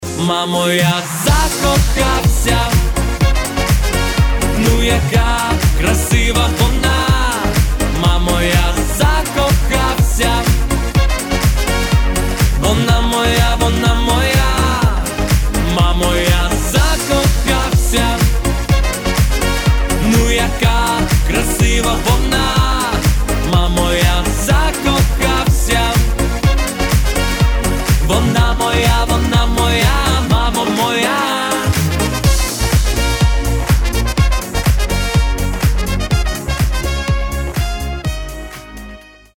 • Качество: 192, Stereo
поп
веселые
инструментальные